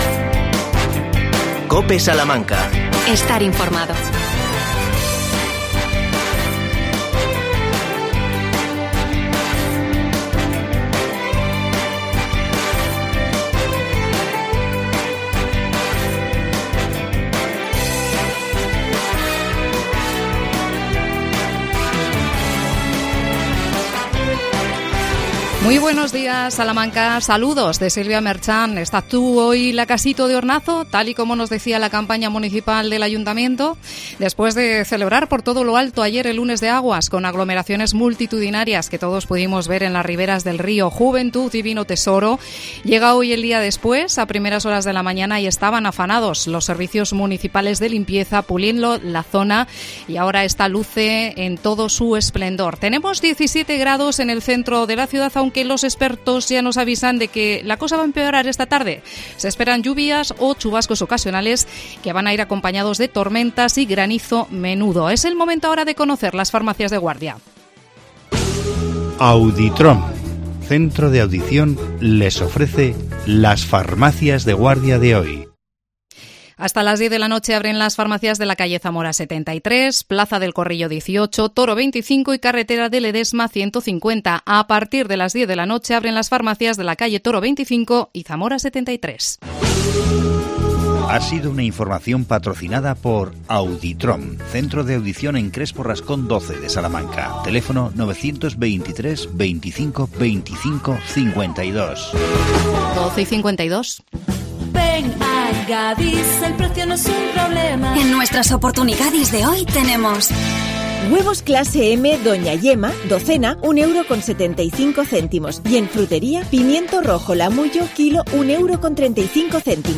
AUDIO: Microespacio Ayuntamiento de Salamanca. Entrevista a la concejala de Salud Pública María José Fresnadillo.